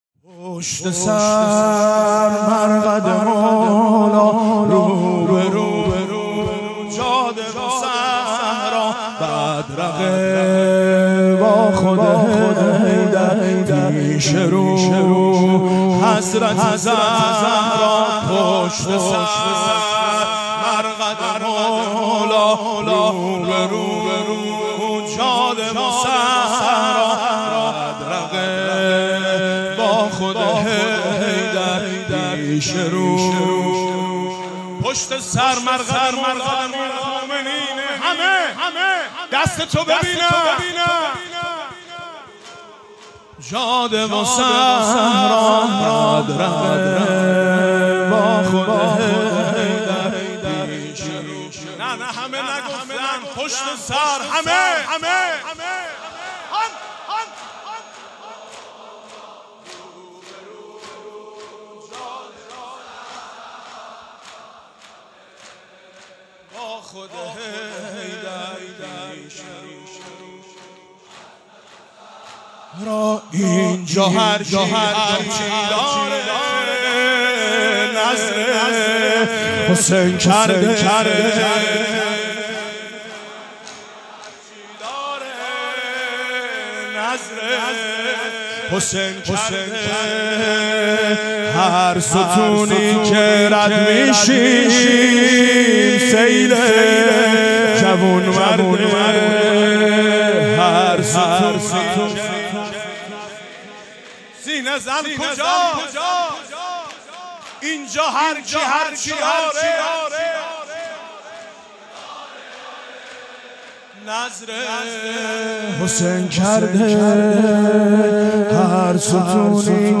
بشنوید/ مداحی حاج میثم مطیعی در مسجد حنانه (نجف اشرف)